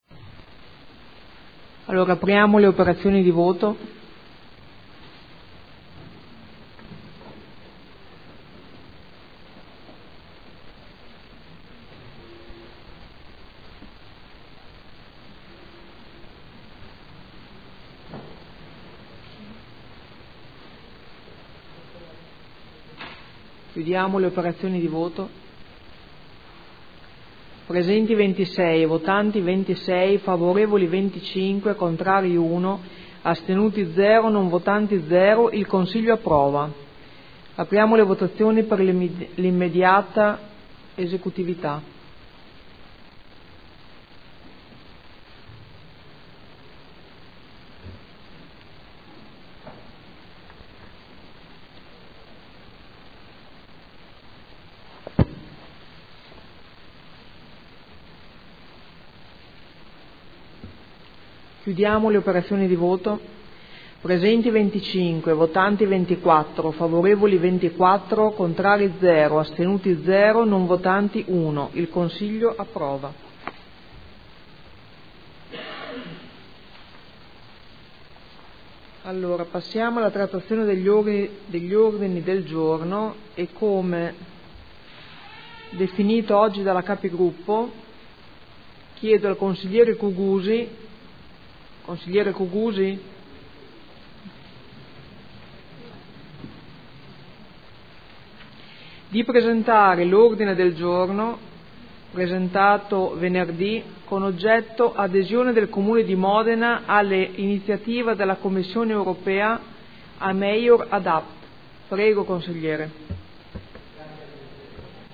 Seduta del 16 ottobre. Proposta di deliberazione: Individuazione e nomina dei componenti dei Consigli di Quartiere (Conferenza Capigruppo del 16 ottobre 2014).